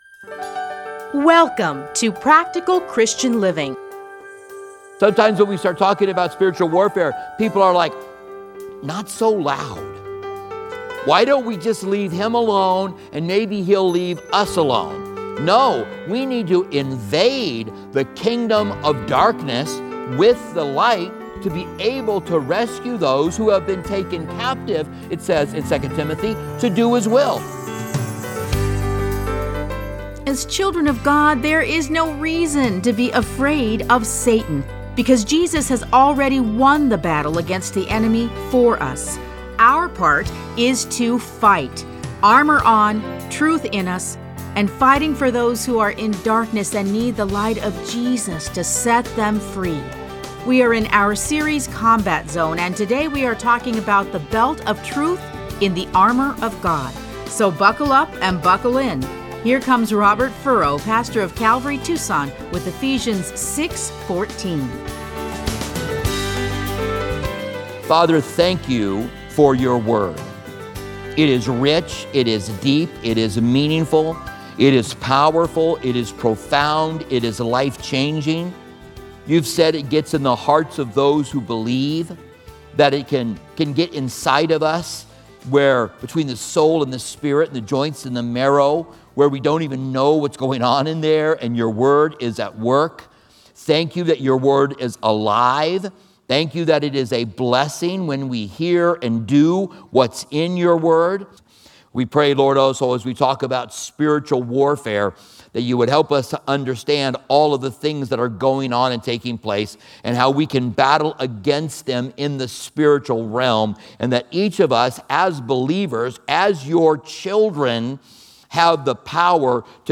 A Study in Ephesians 6:14